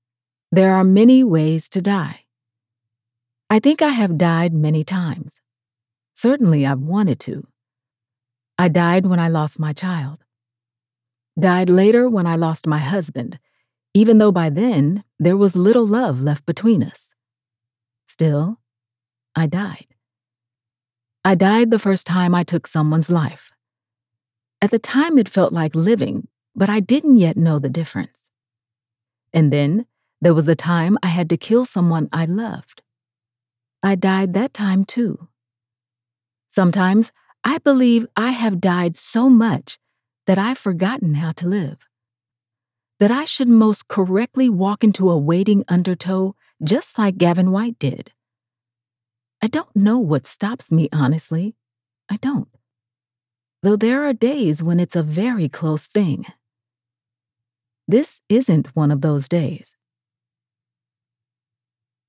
Female 1st POV Assassin Thriller
My voice is often described as warm and feminine, with a depth that brings a unique richness to every story I narrate.
I believe in delivering high-quality audio, and to achieve this, I use top-notch home studio equipment, including a Double-Walled VocalBooth, Neumann TLM 102, RØDE NT1 5th Gen, Sennheiser 416, and a Focusrite Scarlett 2i2 interface.